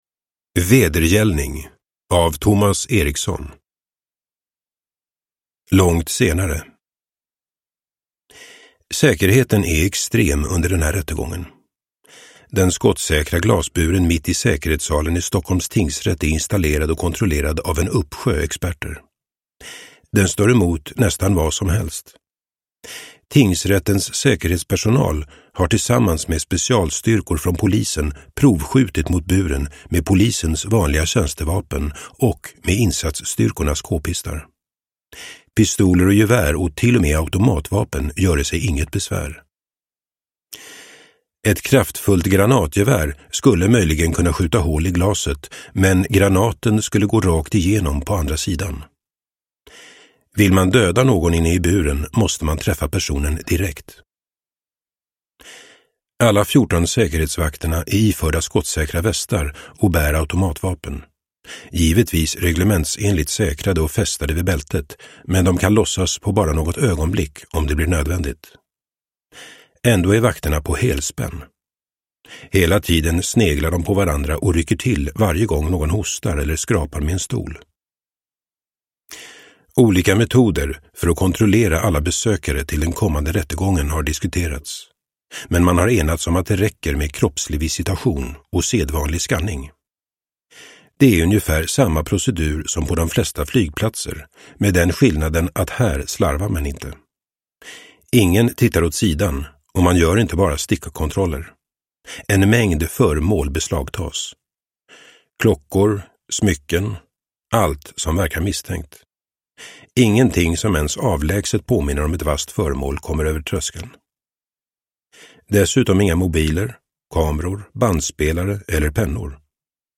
Vedergällning – Ljudbok – Laddas ner